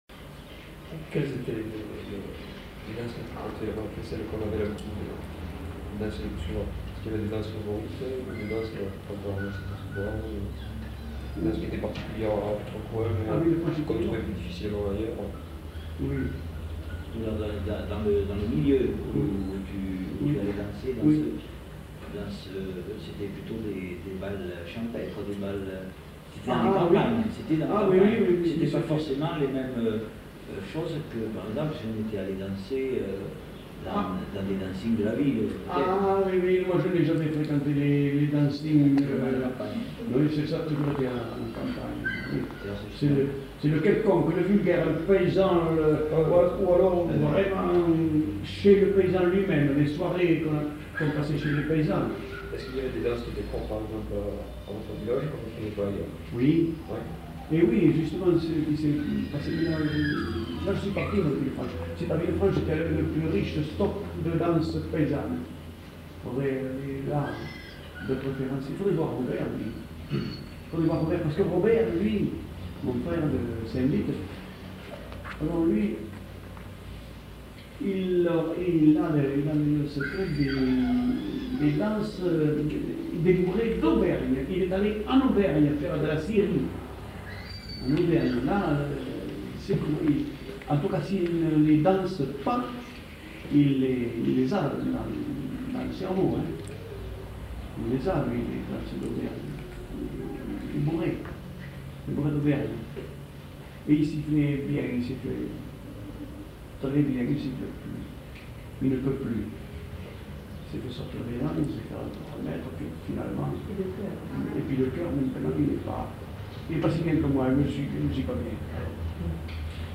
Lieu : Foulayronnes
Genre : témoignage thématique